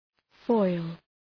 Shkrimi fonetik {fɔıl}